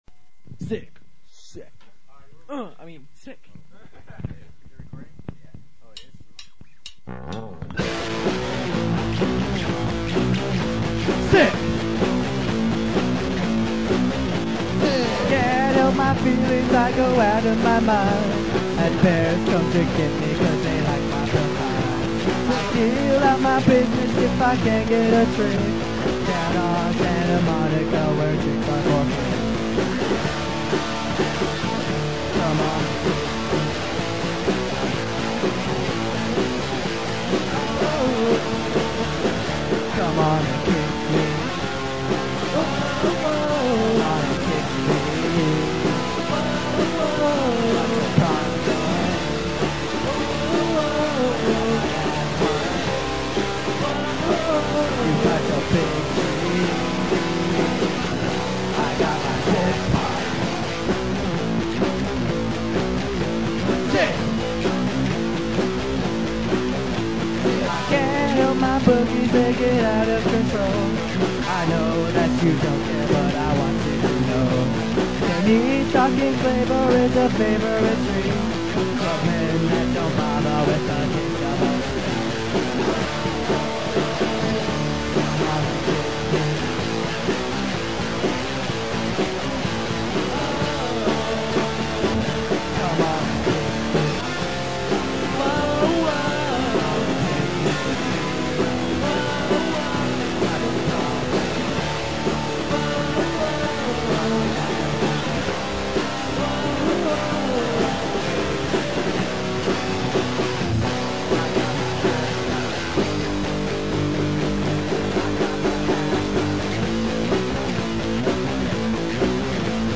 Guitar
Turntables
Drums/Back-up Vocals
Bass/Vocals